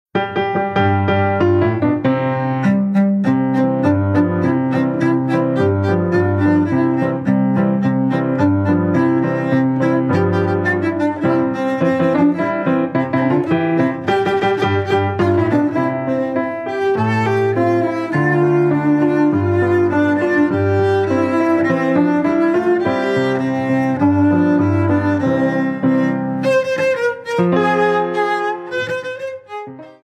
GRABADO EN planet estudio, Blizz producciónes
SOPRANO
VIOLONCELLO SOLO Y ENSAMBLES
PIANO
GUITARRA
PERCUSIONES
VIOLÍN
FLAUTA